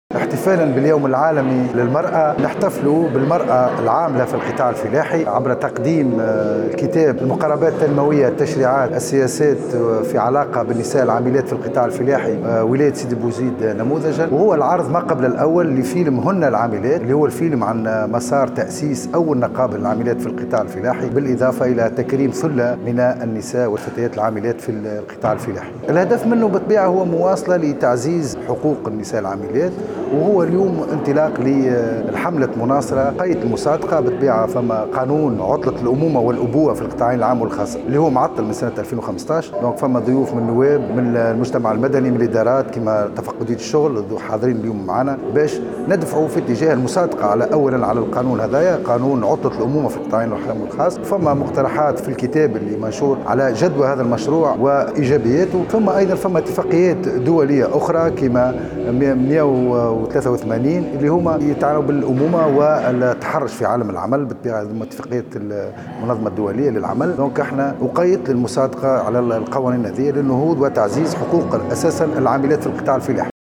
سيدي بوزيد : جمعية الحراك الثقافي تحتفل باليوم العالمي للمرأة ( صور + تصريح )